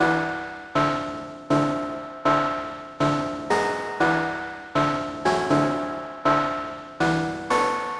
Tag: 120 bpm Trap Loops Synth Loops 1.35 MB wav Key : Unknown FL Studio